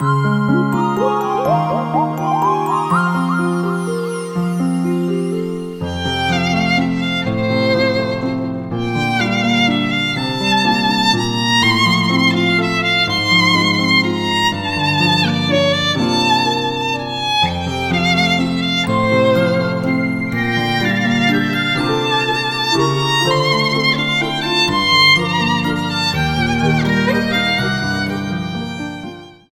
A live recorded violin arrangement